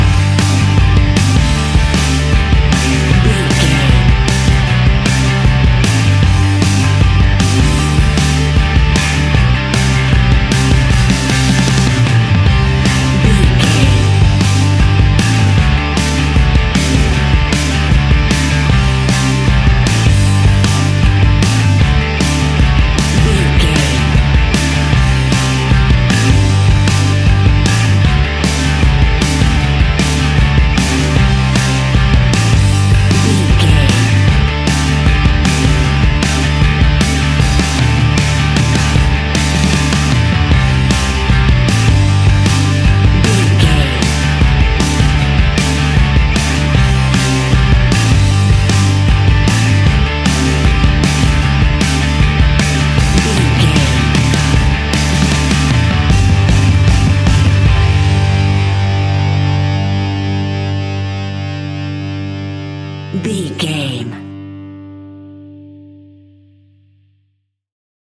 Epic / Action
Fast paced
Ionian/Major
SEAMLESS LOOPING?
hard rock
blues rock
distortion
instrumentals
rock guitars
Rock Bass
Rock Drums
heavy drums
distorted guitars
hammond organ